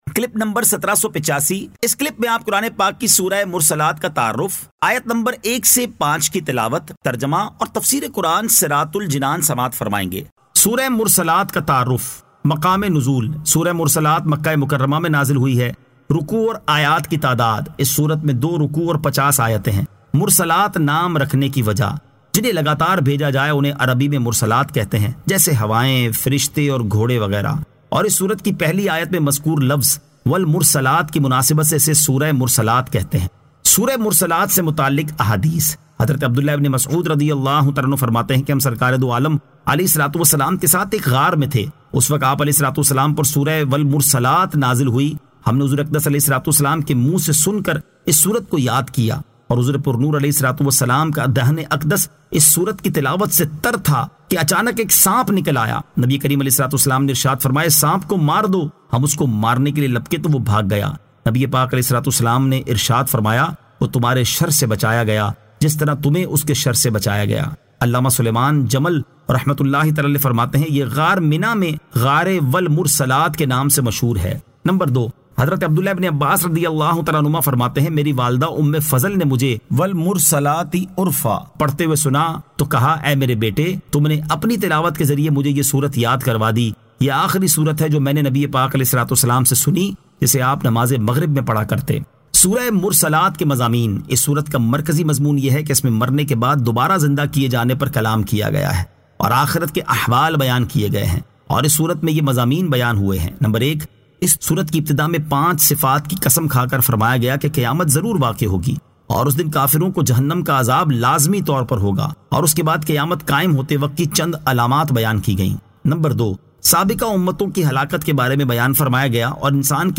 Surah Al-Mursalat 01 To 05 Tilawat , Tarjama , Tafseer